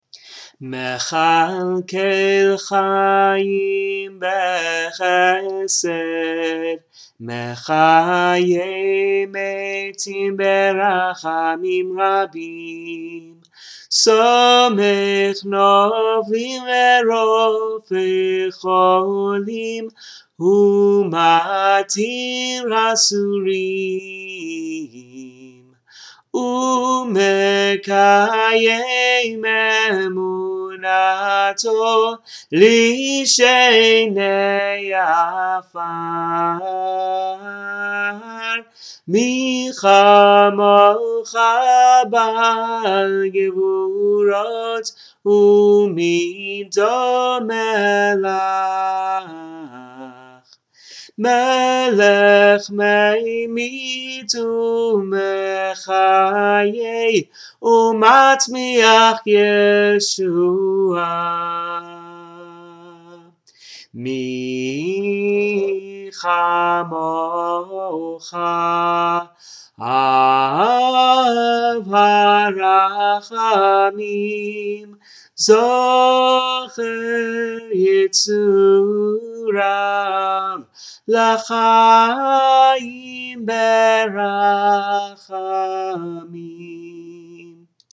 Mekhalkel hayyim be-hesed -- the special high holy melody to this passage from the Amidah